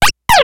Cri de Scrutella dans Pokémon X et Y.